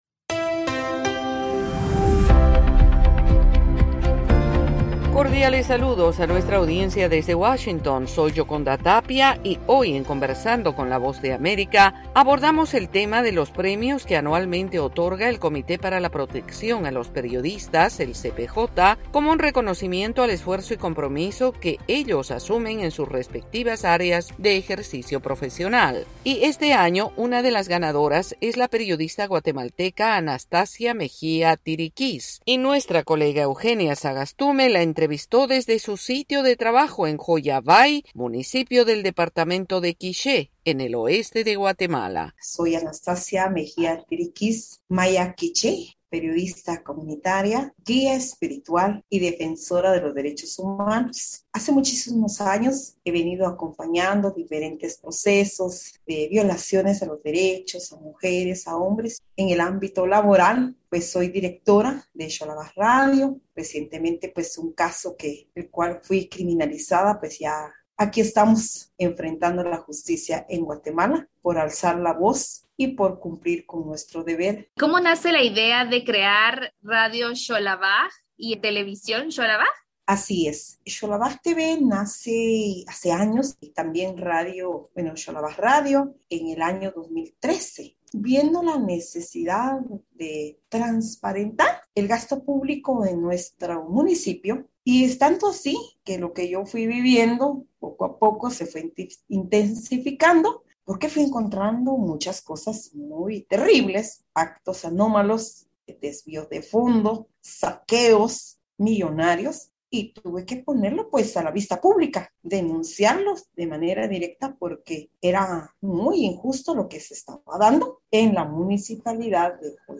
Conversando con la VOA